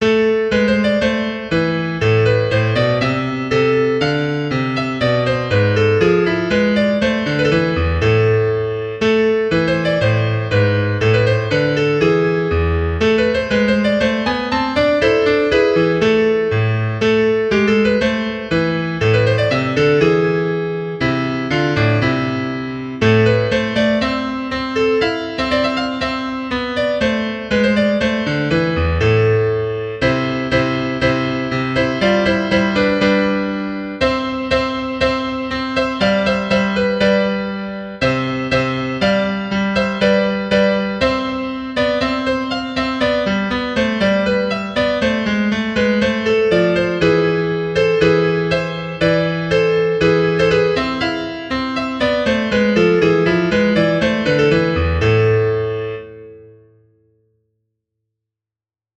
Key: a minor